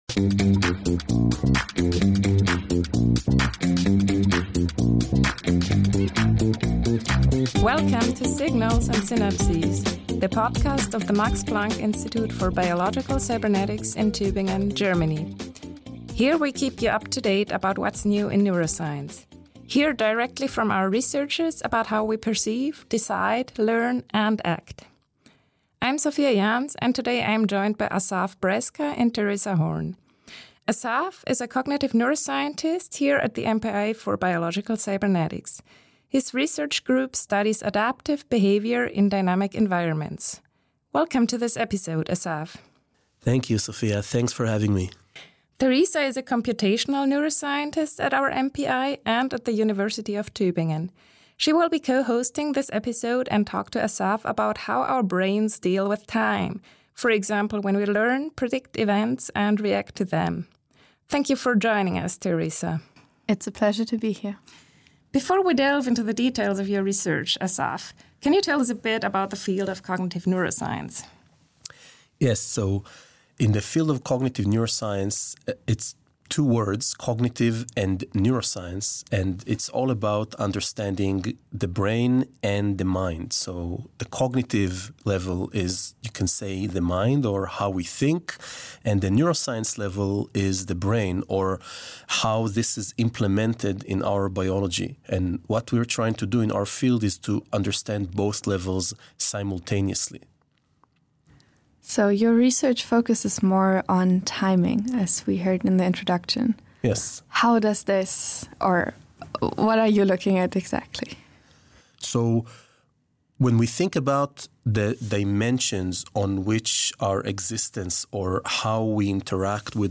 Insights into neuroscience for cross-disciplinary thinkers and curious minds alike! The series features interviews with researchers discussing their latest research as well as their challenges and ideas for the future.